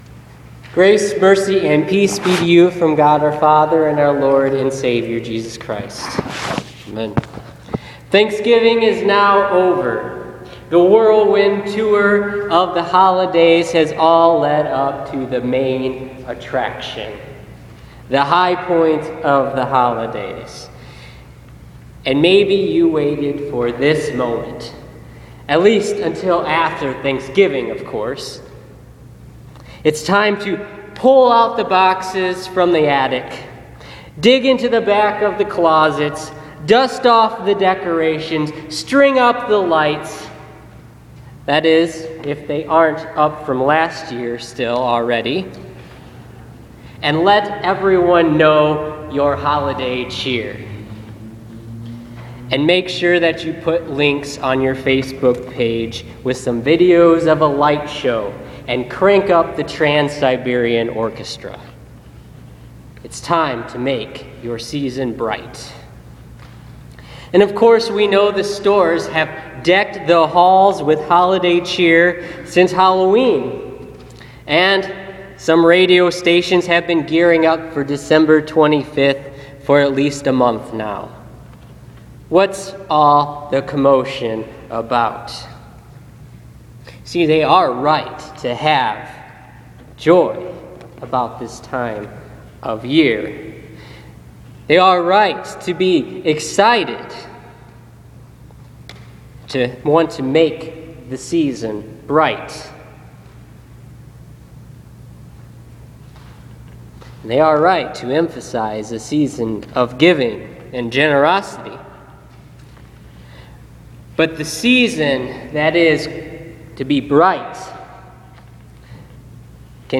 Listen to this week’s sermon on Isaiah 2:1-5 for the 1st week of Advent.